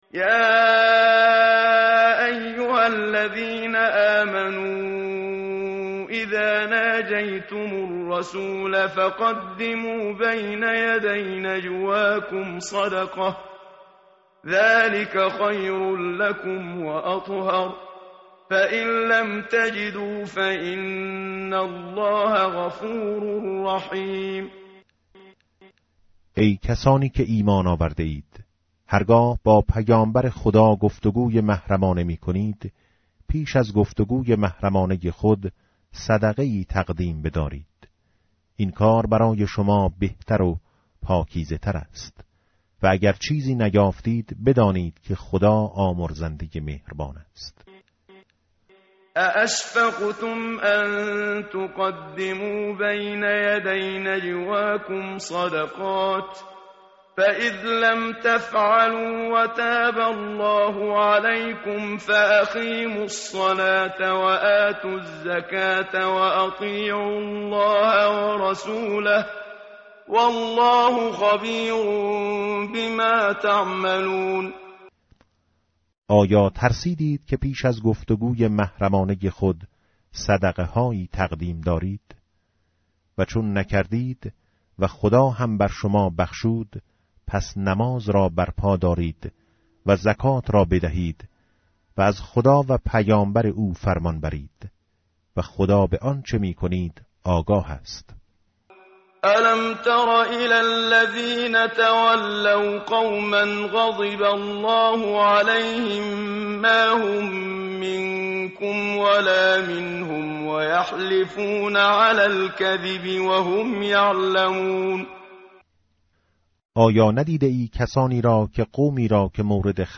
tartil_menshavi va tarjome_Page_544.mp3